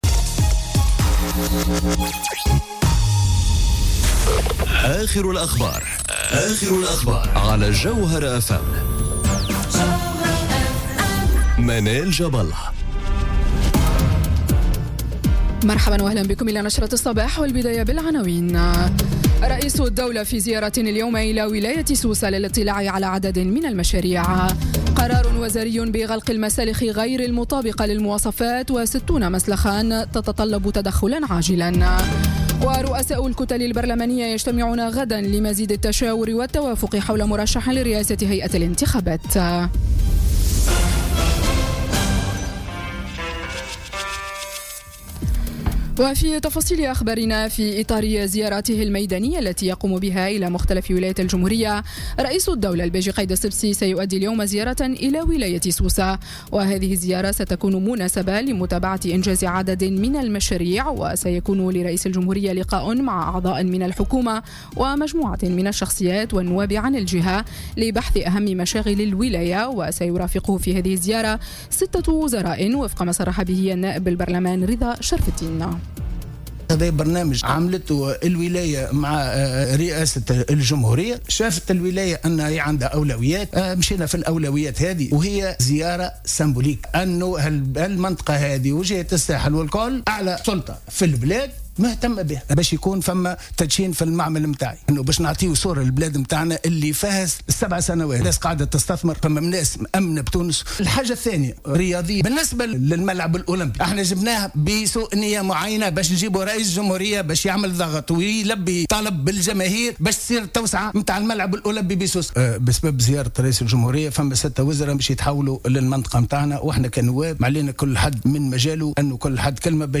نشرة أخبار السابعة صباحا ليوم الإربعاء 4 أكتوبر 2017